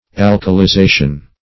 Meaning of alkalization. alkalization synonyms, pronunciation, spelling and more from Free Dictionary.
Search Result for " alkalization" : The Collaborative International Dictionary of English v.0.48: Alkalization \Al`ka*li*za"tion\, n. [Cf. F. alcalisation.]